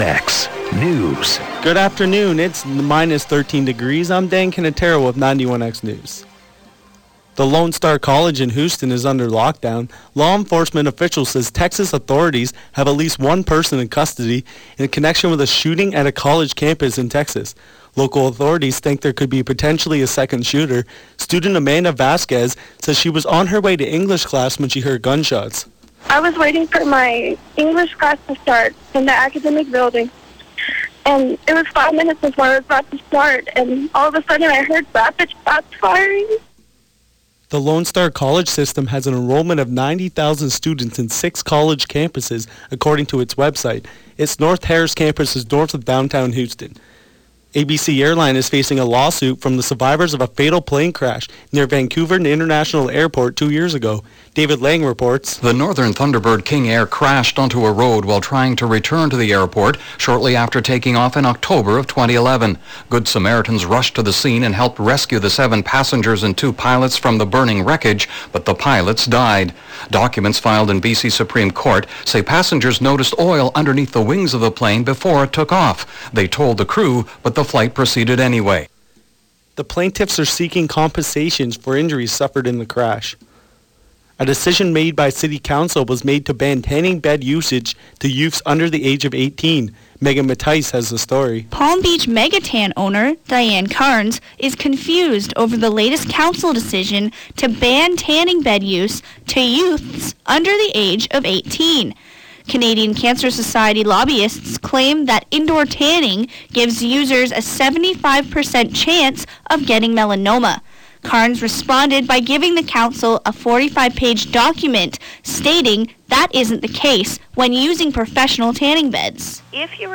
january 22 4pm newscast